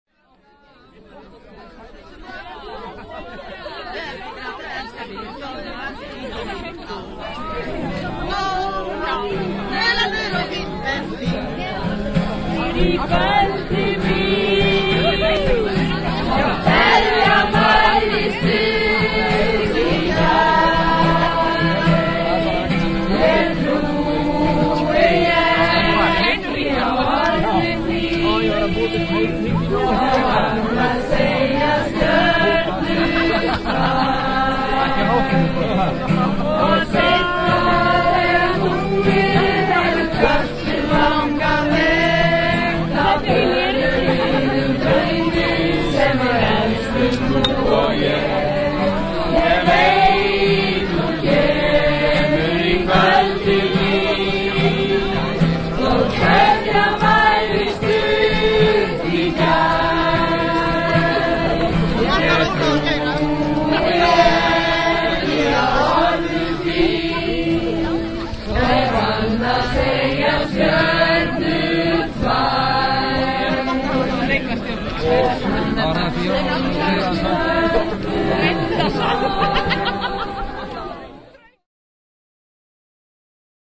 Fieldrecording and Modulation
The field recordings originate from Iceland and were recorded between July and October 2002. They include conversations, songs, sounds of water, steam and seismic noises.
28.7.2002, Porsmörk
After grilling lamb chops a campfire is lit and a fine ime is had by all.
feier_am_fluss.raw_audio.gruenrekorder13.mp3